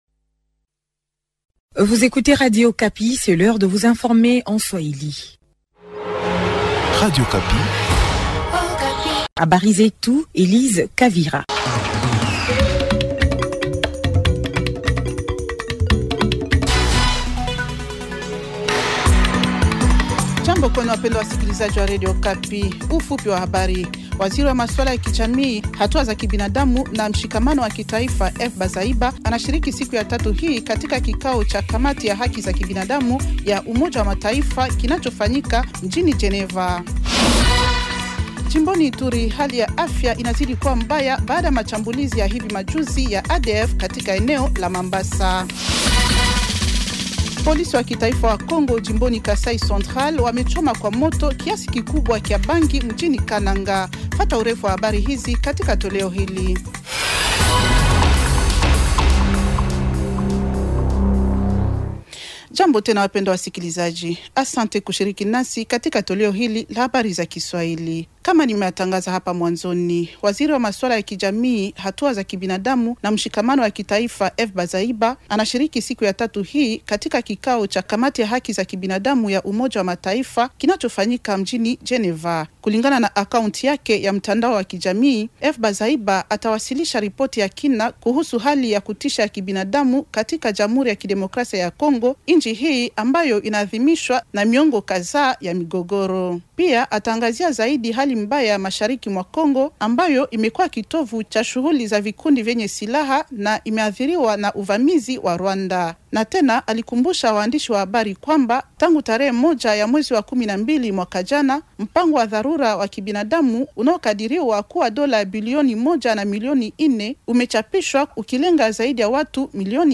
Journal swahili de mercredi soir 250326